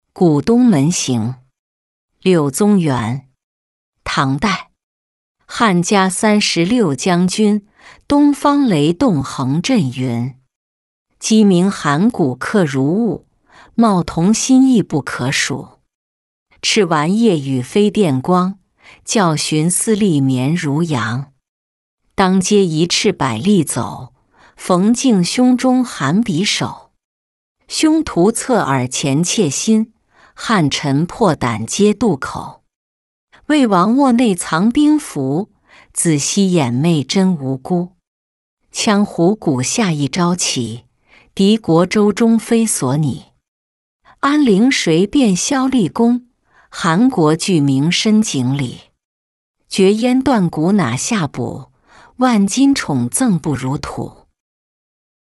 古东门行-音频朗读